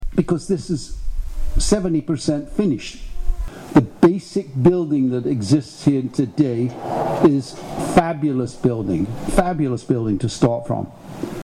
A key exchange ceremony took place today (Monday) at the former Pinecrest Memorial Elementary School between members of the LoveSong organization and Prince Edward County, turning the property officially over to the group that aims to create affordable housing for seniors.